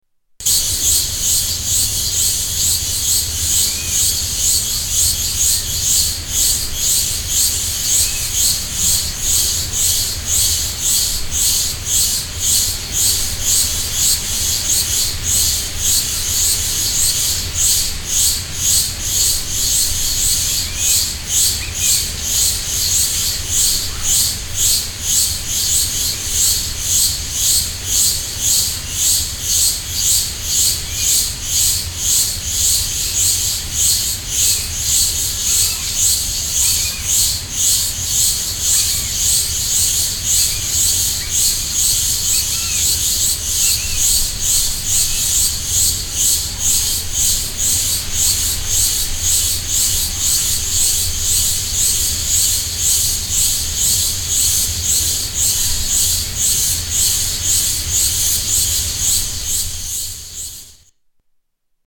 Forest near a windy beach on Marajo Island